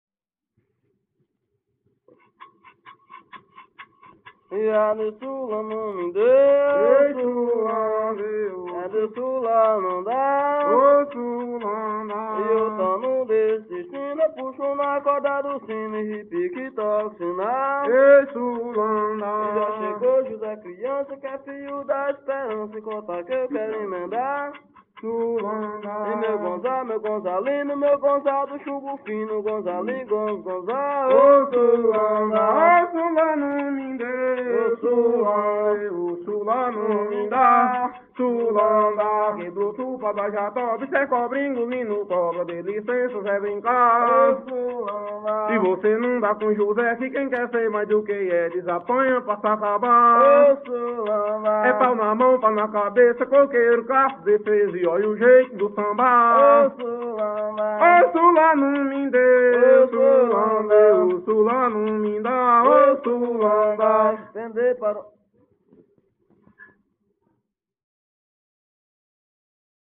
Coco parcelado -“”Oh sula não me deu”” - Acervos - Centro Cultural São Paulo